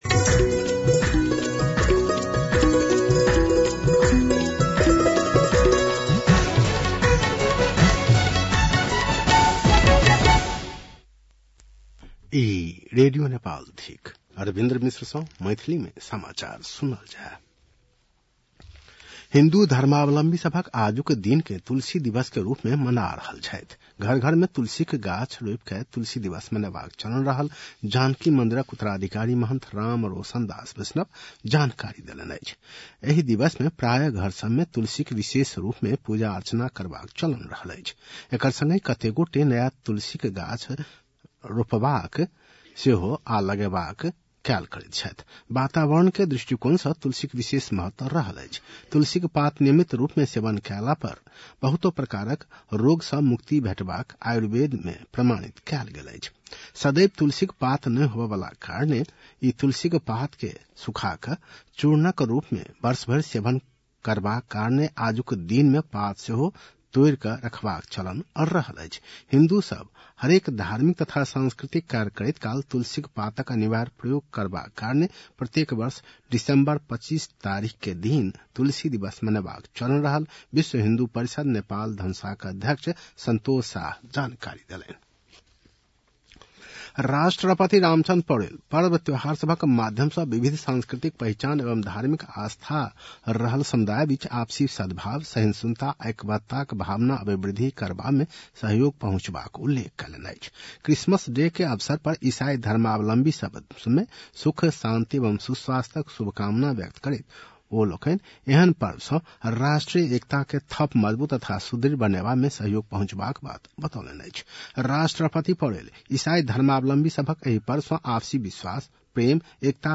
मैथिली भाषामा समाचार : ११ पुष , २०८१
Maithali-News-9-10.mp3